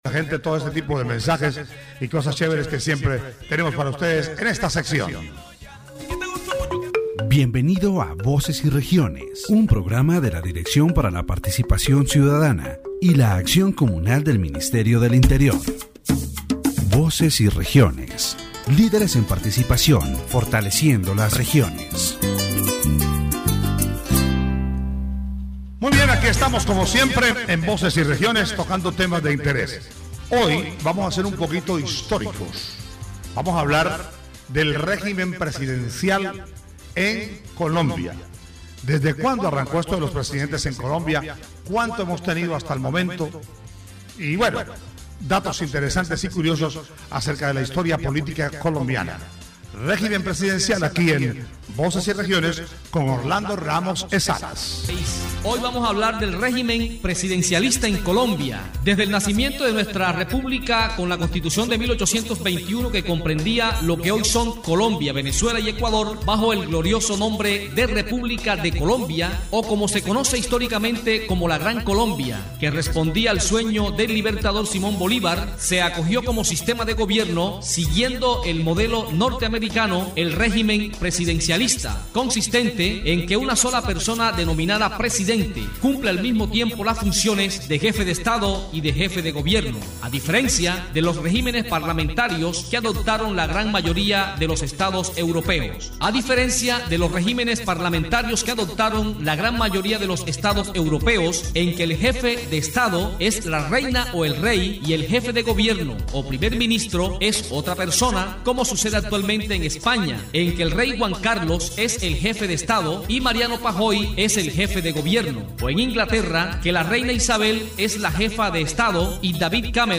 The "Voces y Regiones" program on Arjona Estéreo, supported by the Ministry of the Interior, explored the evolution of Colombia’s presidential system.